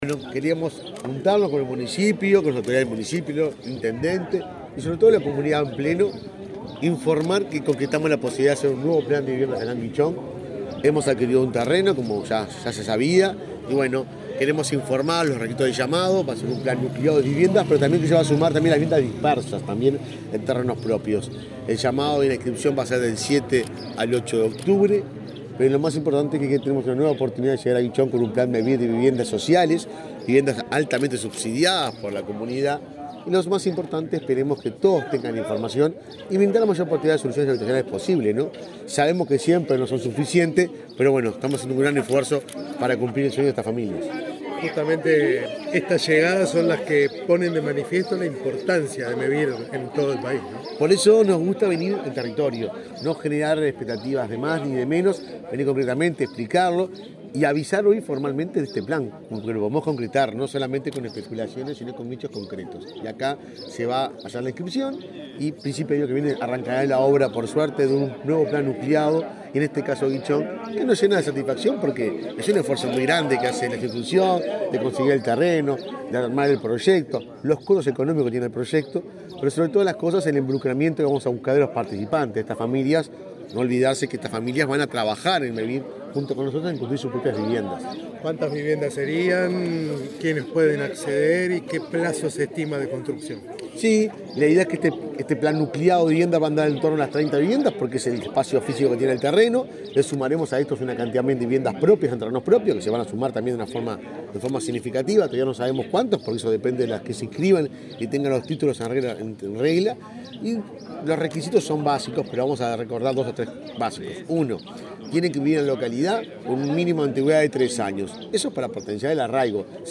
Declaraciones del presidente de Mevir